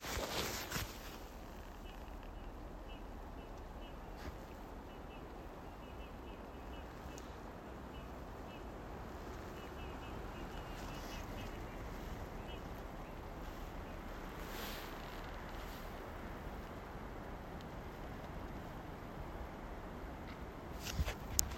снегирь, Pyrrhula pyrrhula
СтатусСлышен голос, крики